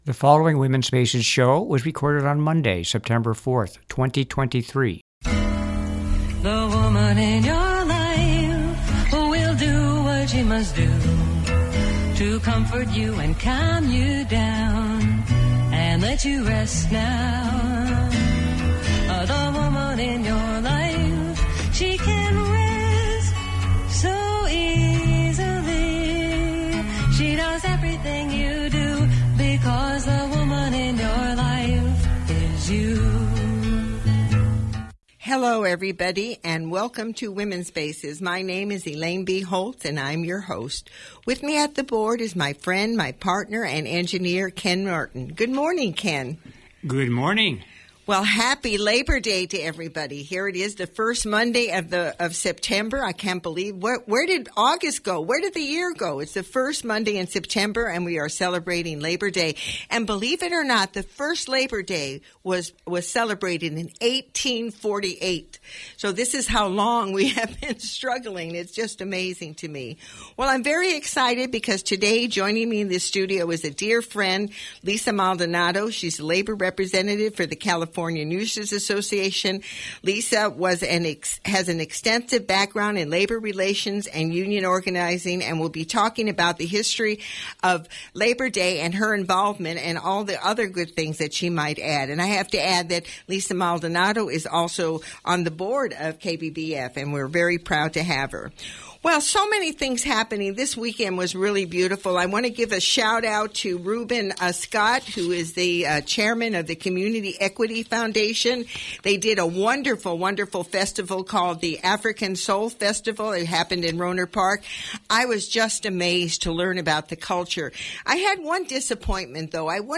Today is Labor Day.